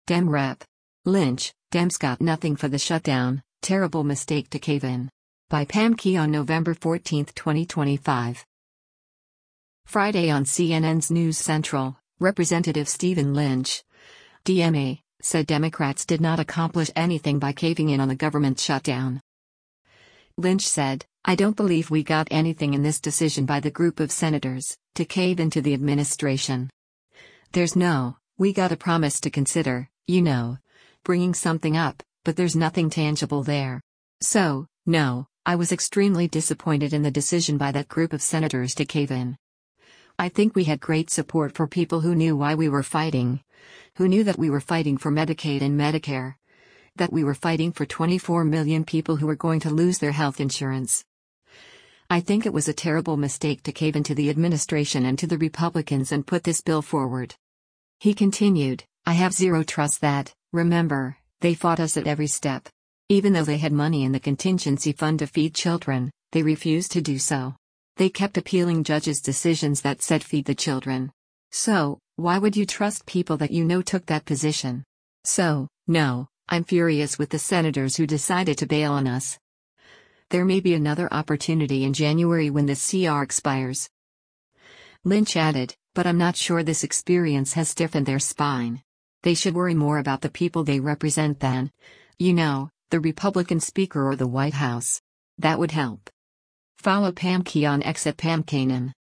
Friday on CNN’s “News Central,” Rep. Stephen Lynch (D-MA) said Democrats did not accomplish anything by caving in on the government shutdown.